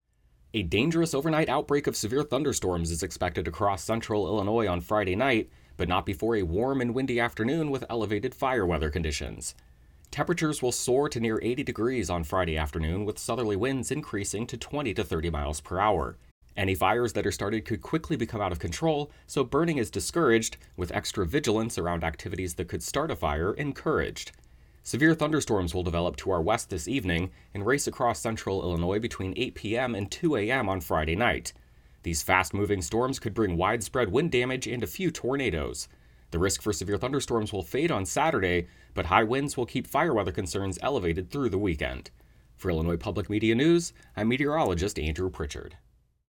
Friday morning forecast